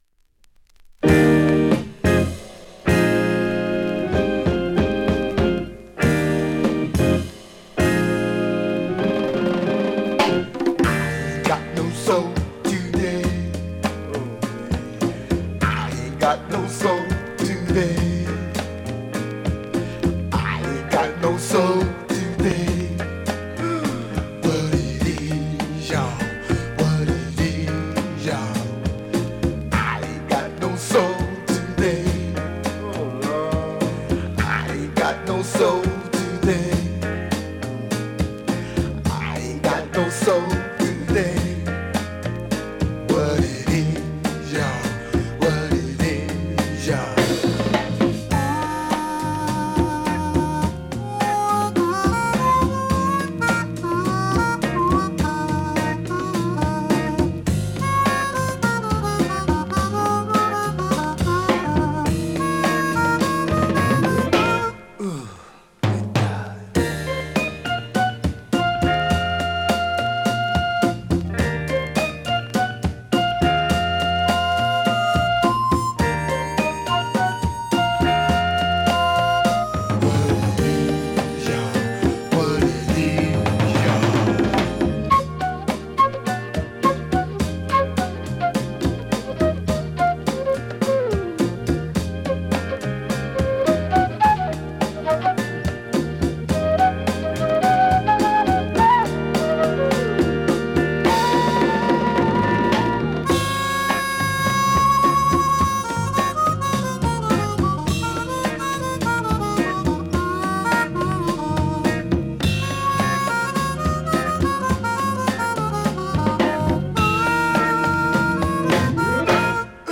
現物の試聴（両面すべて録音時間5分）できます。
ローライダー・ソウル/チカーノ・ソウル